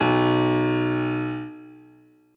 b_basspiano_v127l1o2b.ogg